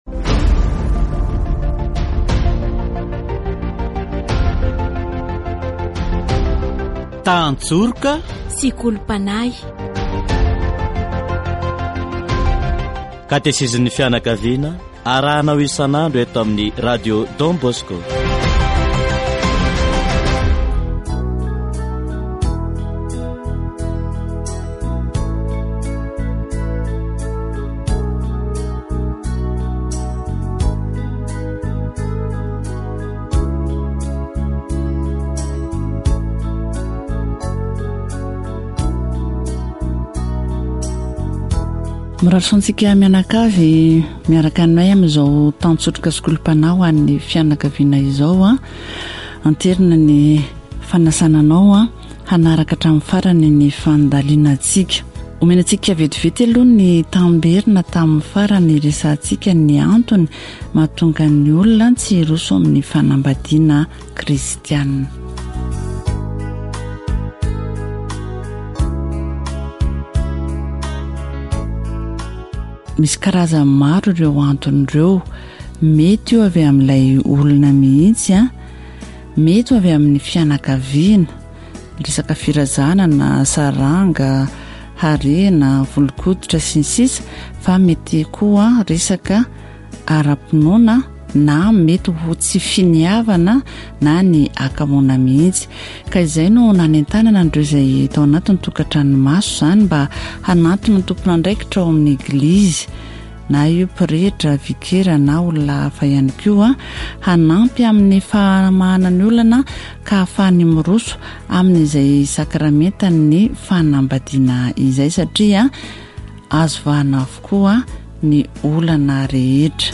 Catéchèse sur la famille et le sacrement du baptême